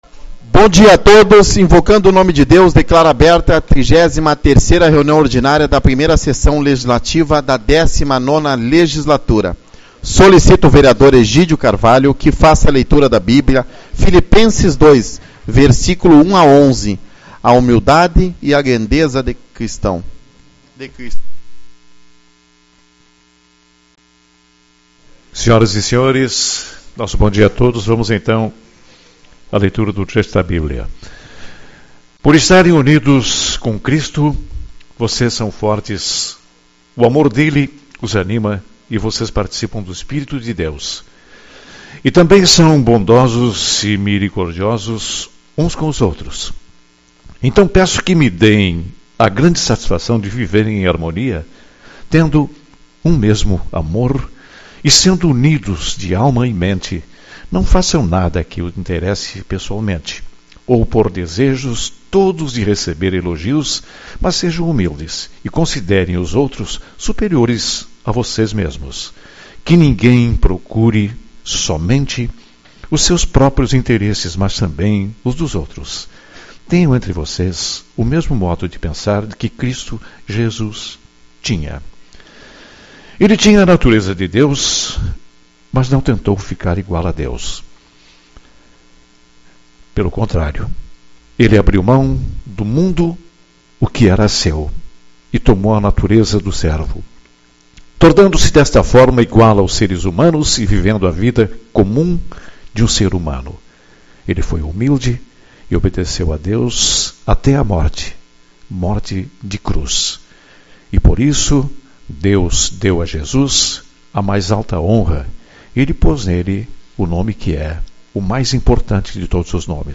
03/06 - Reunião Ordinária